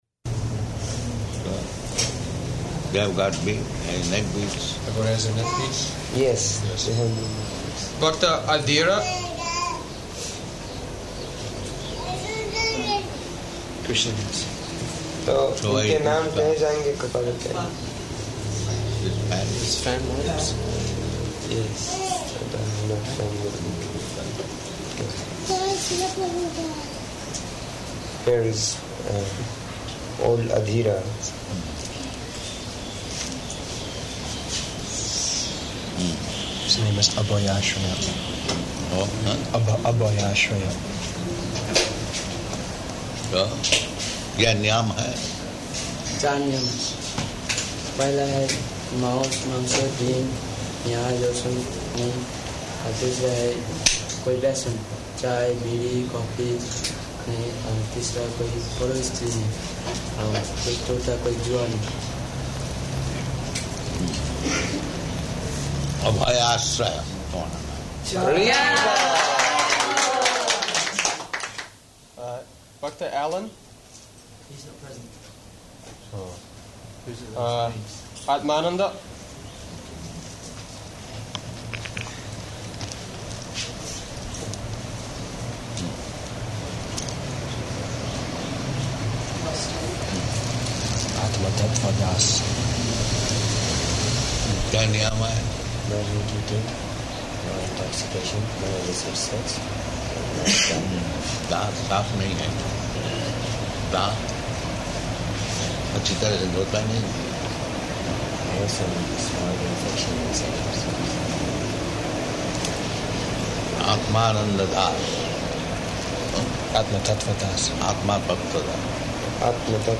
Initiations --:-- --:-- Type: Initiation Dated: August 31st 1976 Location: Delhi Audio file: 760831IN.DEL.mp3 Prabhupāda: They have got neckbeads?